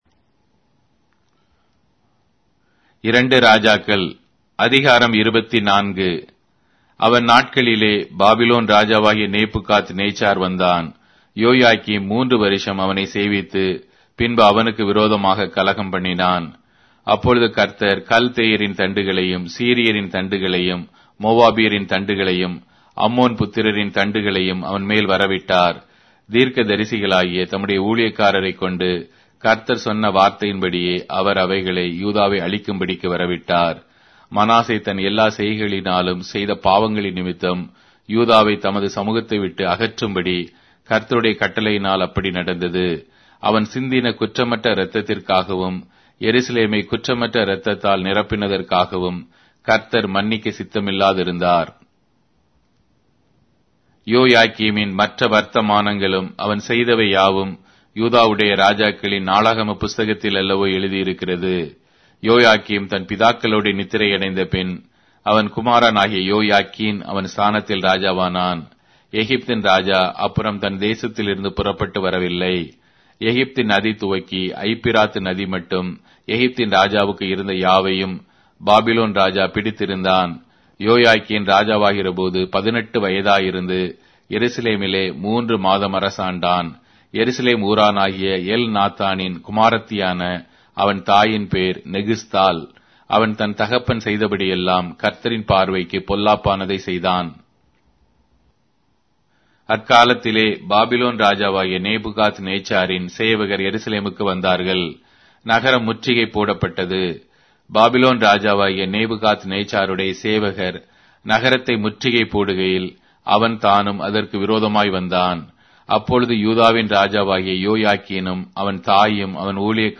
Tamil Audio Bible - 2-Kings 16 in Esv bible version